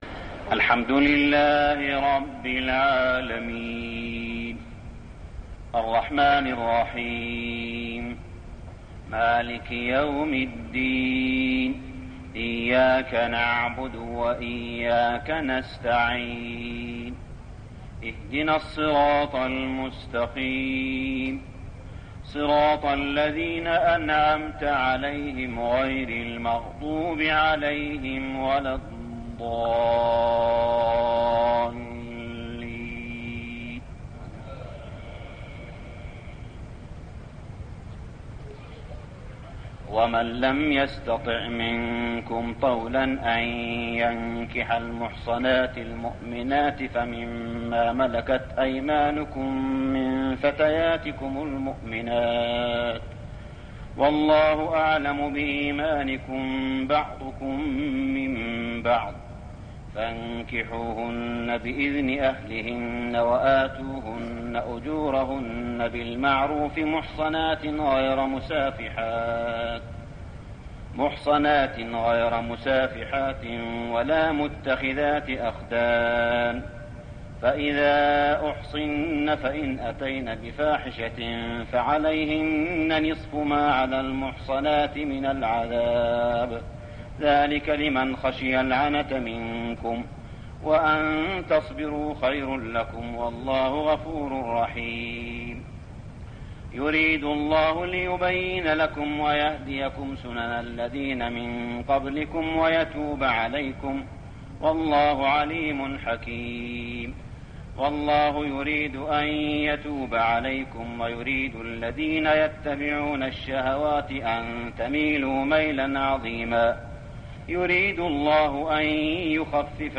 صلاة التراويح ليلة 6-9-1410هـ سورة النساء 25-87 | Tarawih Prayer Surah An-Nisa > تراويح الحرم المكي عام 1410 🕋 > التراويح - تلاوات الحرمين